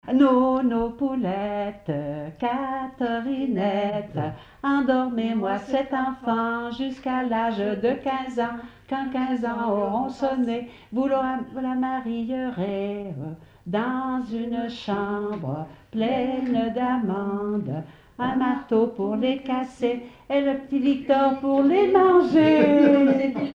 berceuse
Pièce musicale inédite